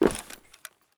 255081e1ee Divergent / mods / Soundscape Overhaul / gamedata / sounds / material / human / step / tin4.ogg 34 KiB (Stored with Git LFS) Raw History
tin4.ogg